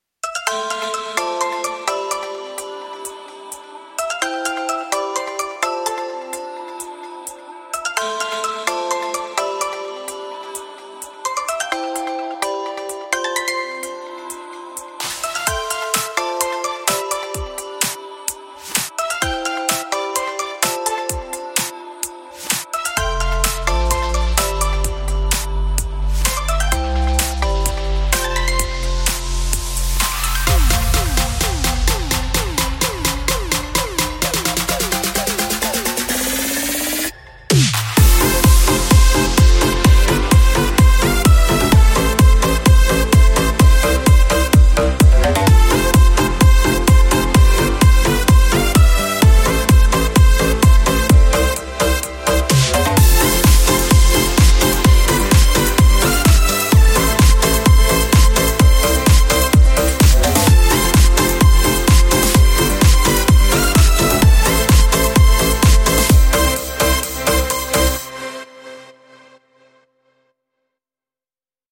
这些重击的EDM样本具有出色的制作效果，包括双桨击打，快活的军鼓，结晶的踩hat和各种独特的打击乐器声音。
– 300个EDM鼓样本
– 75个军鼓